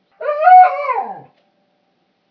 sound_library / animals / owls